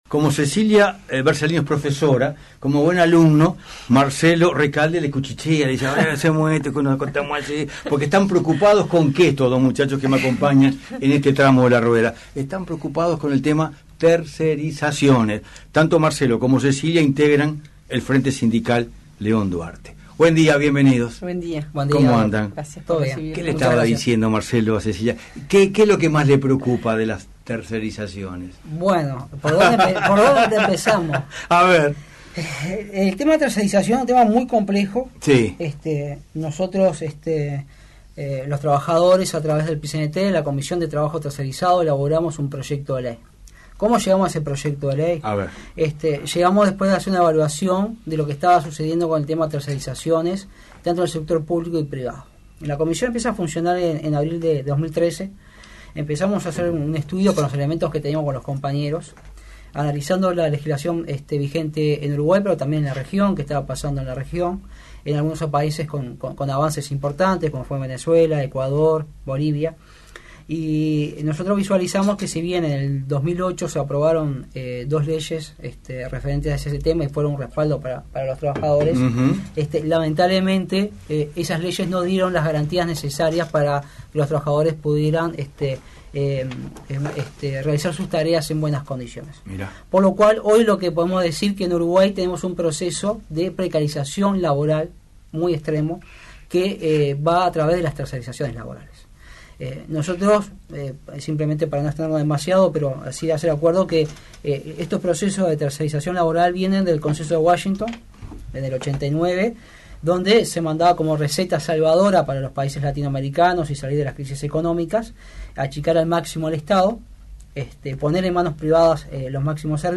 programa radial